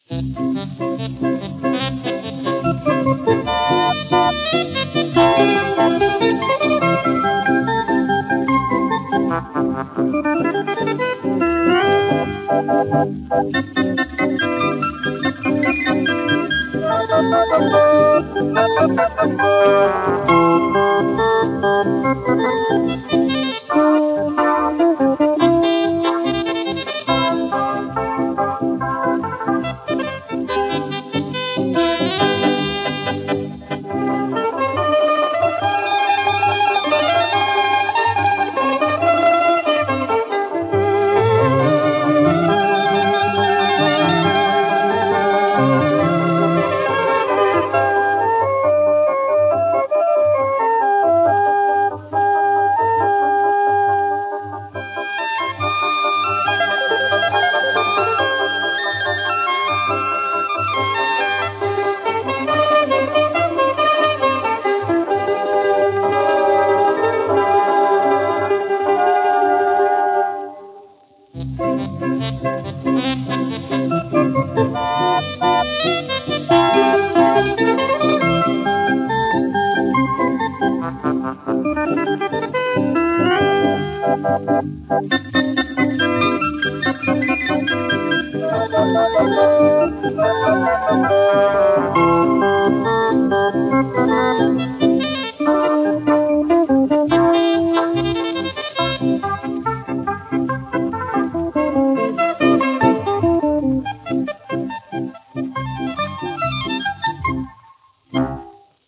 Осмелился почистить данные пьесы от пластиночного шума.
Звук стал похож на вещательный.